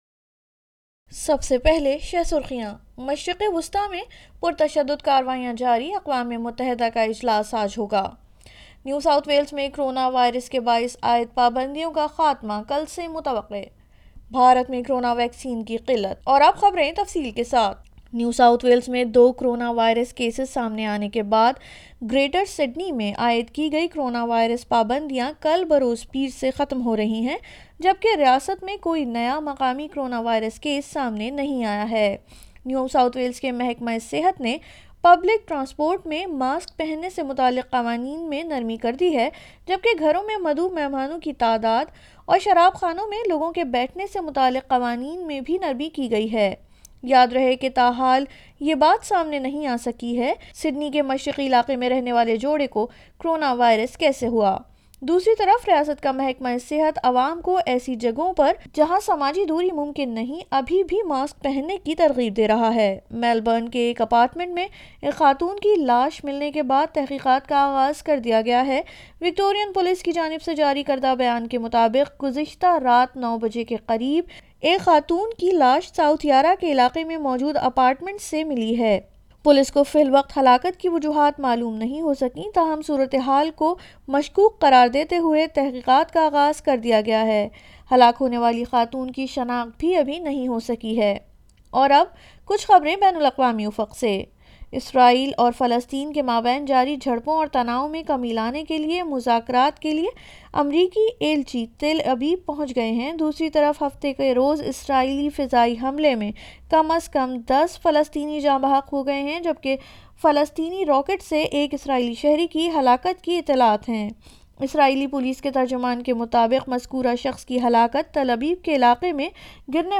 Urdu news Sunday 16 May 2021